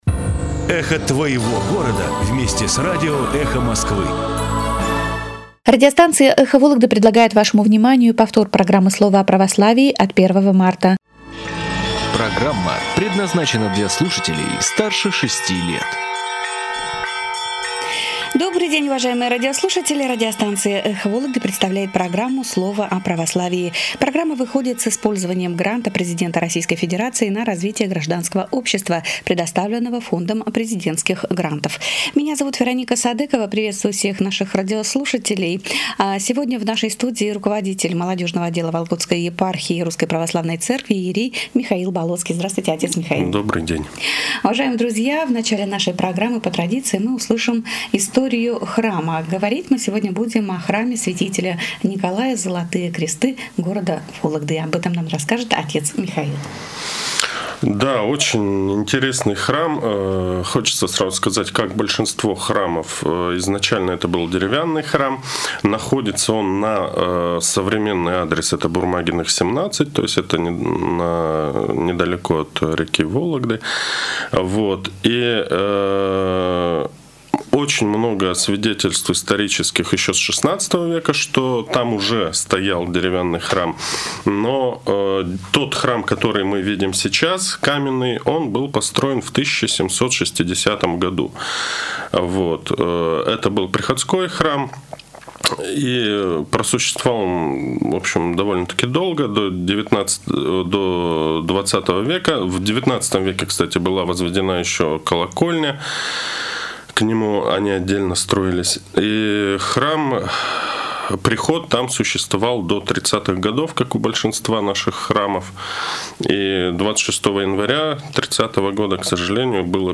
Священник Вологодской епархии выступил в прямом эфире радиостанции "Эхо Вологды"